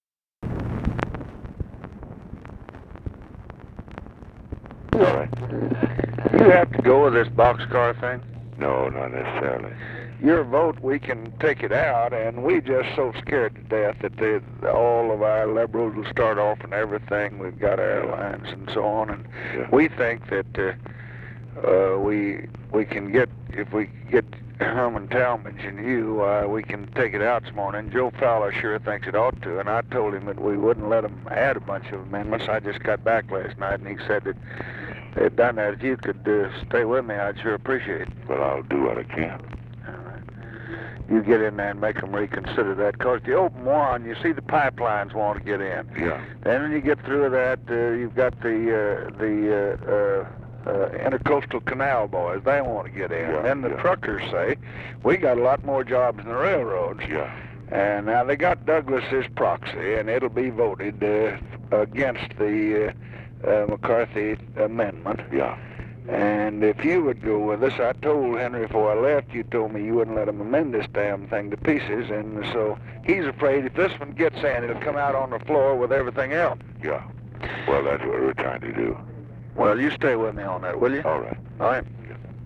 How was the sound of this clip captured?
Secret White House Tapes